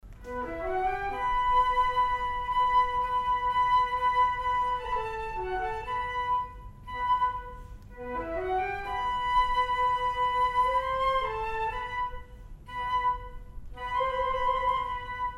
これは、常に一緒に吹いているパートの仲間ですから、安心して演奏出来ます。
それは、２番フルートとアルトフルートのユニゾンで吹くようなアレンジになっていました。
他のパートは休みですから、何もすることはありません。
もちろん、本番の演奏をノーカットで聴くことができますが、ごく一部のものだけ、ほんの少し編集してあります。